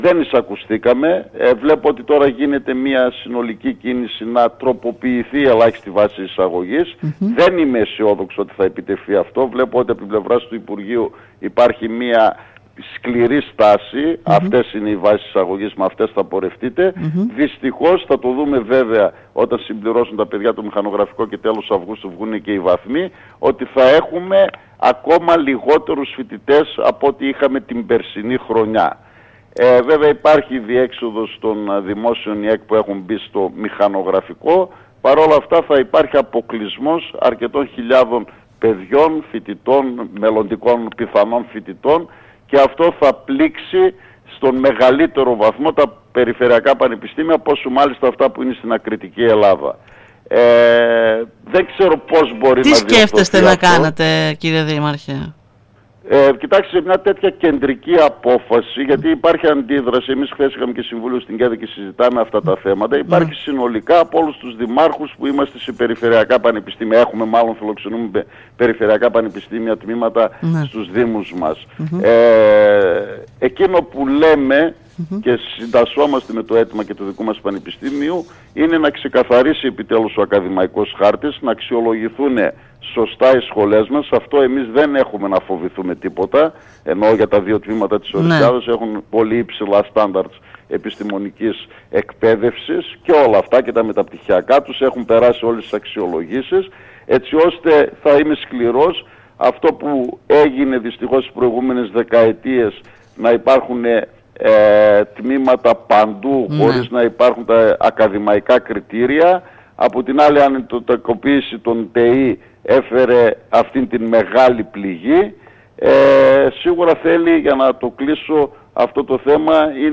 Μιλώντας σήμερα στην ΕΡΤ Ορεστιάδας ο κ. Μαυρίδης δήλωσε πως δεν είναι αισιόδοξος σε μια πιθανή αλλαγή των βάσεων  από το Υπουργείο, αν και υπήρξε πρόταση τους από το Φεβρουάριο να υπάρχει απόκλιση στο ποσοστό εισαγωγής μεταξύ περιφερειακών και κεντρικών πανεπιστημίων προς όφελος των πρώτων.